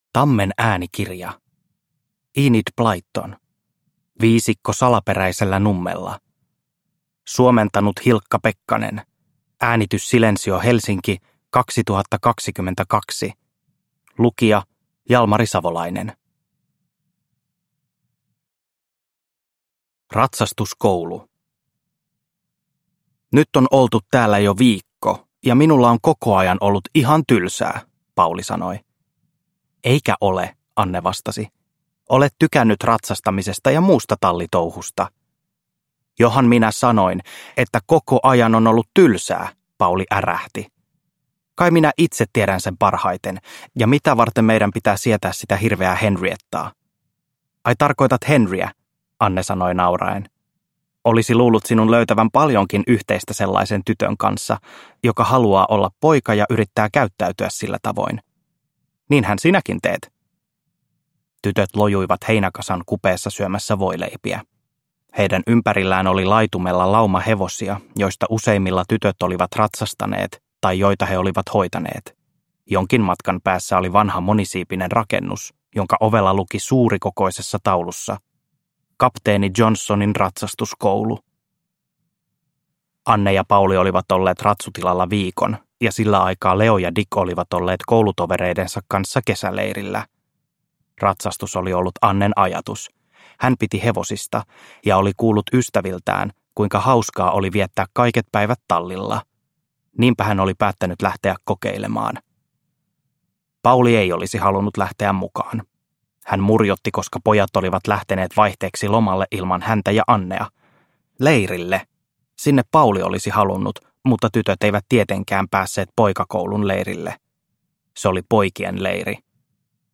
Viisikko salaperäisellä nummella – Ljudbok – Laddas ner